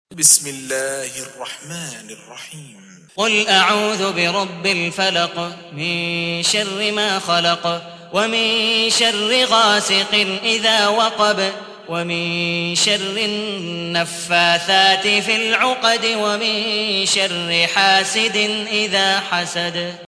تحميل : 113. سورة الفلق / القارئ عبد الودود مقبول حنيف / القرآن الكريم / موقع يا حسين